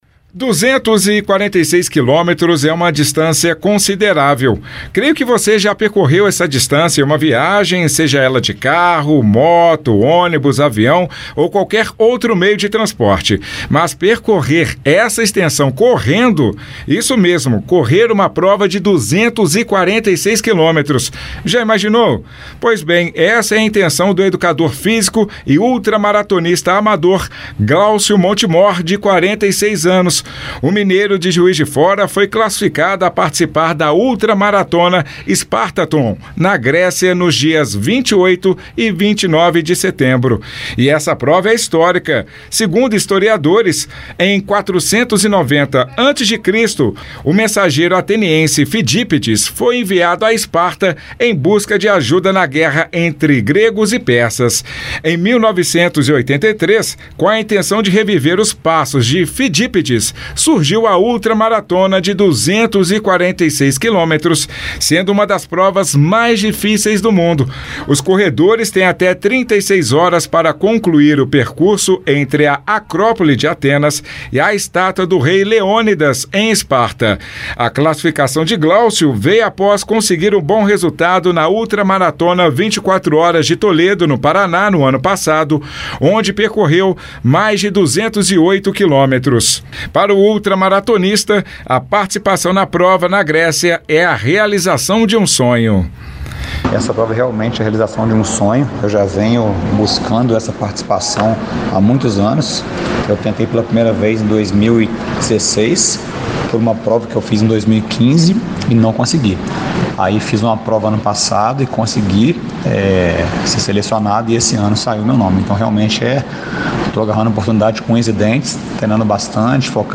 Ultramaratonista-prova-corrida-248-km-Grecia.mp3